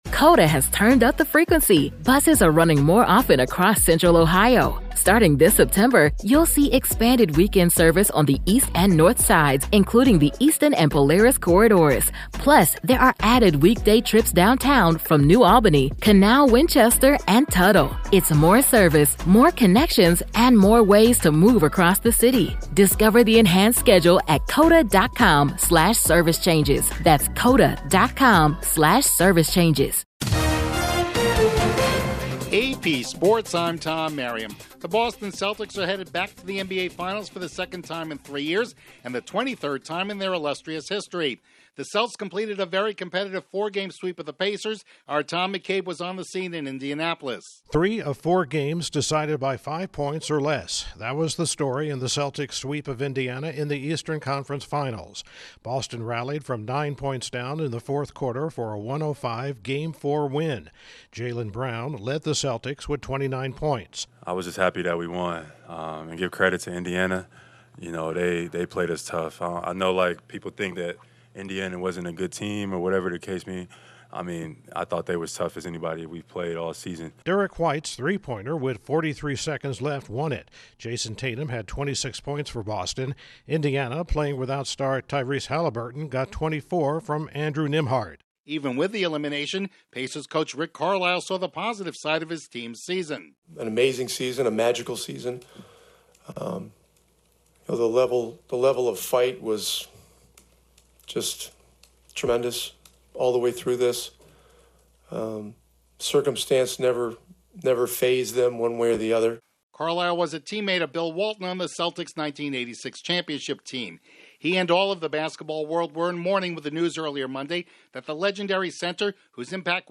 The Celtics return to the NBA Finals, a true basketball legend passes away, the Stars shine again in the Stanley Cup Playoffs, and Rafael Nadal loses his first found match at the French Open. Correspondent